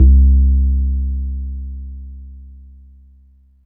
Rounder Bass.wav